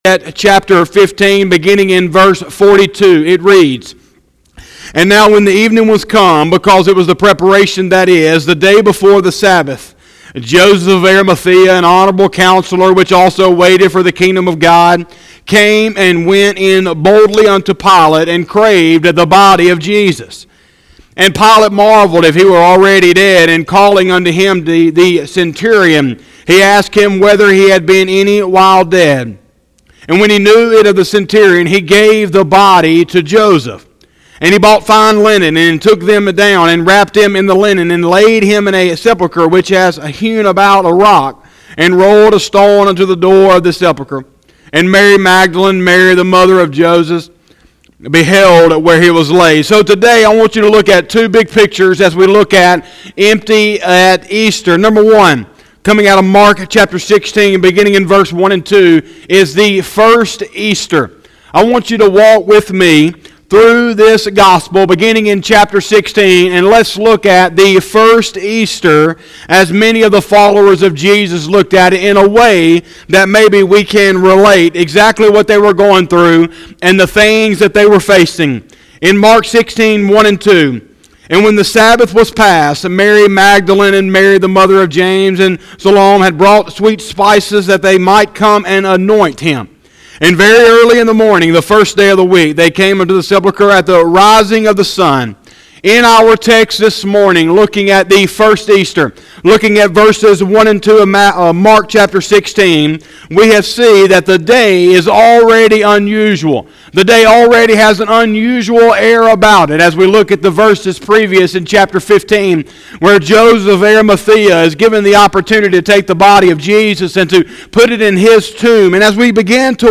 04/12/2020 – Sunday Morning Service